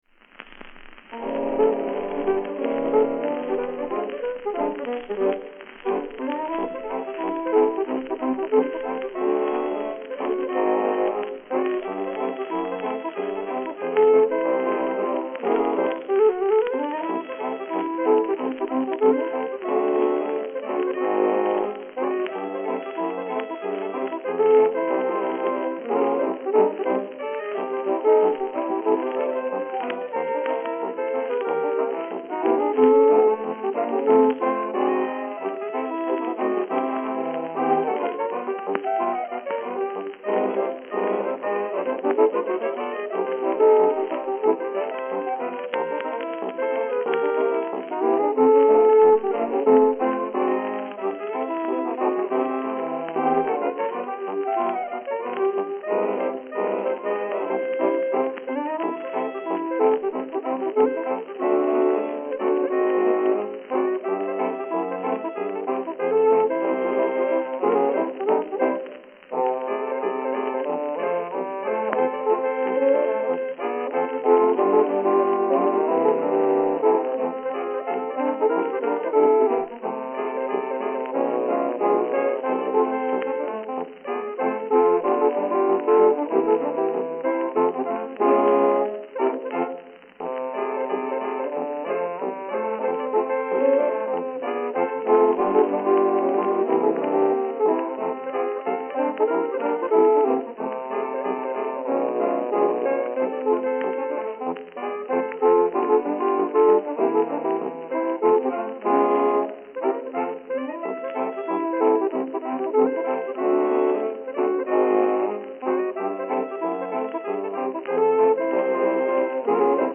Fox-trot